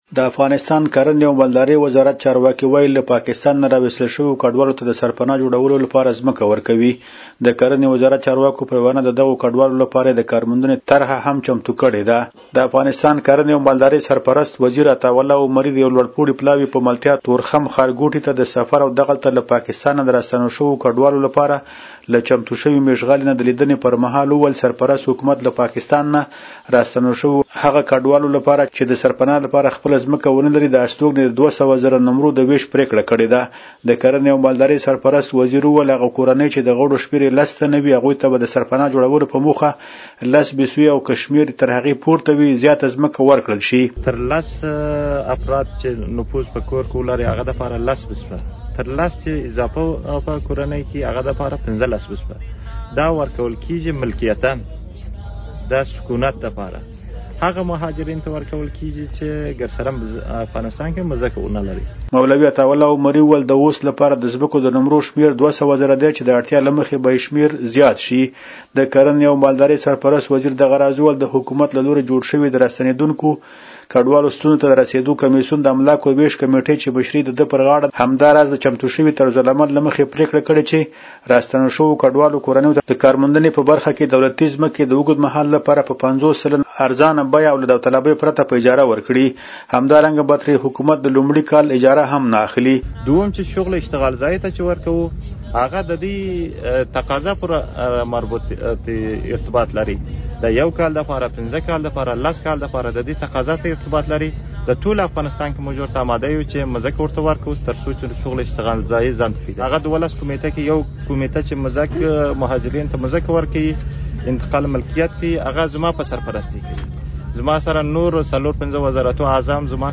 زمونږ خبریال راپور راکړی د افغانستان کرنې او مالدارۍ وزارت چارواکي وایي له پاکستان نه راویستل شویو کډوالو ته د سرپنا جوړولو لپاره زمکه ورکوي.